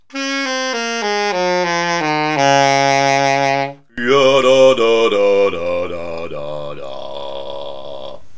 Ein kleines Beispiel zeigt schnell, dass im Gegensatz zur menschlichen Stimme das Saxophon die tiefsten Töne am lautesten wiedergibt:
Links zu sehen das Saxophon und rechts die menschliche Stimme, beide mit der gleichen Tonleiter abwärts des' bis des. Ich habe versucht mit gleichbleibendem Energieaufwand zu spielen und zu singen.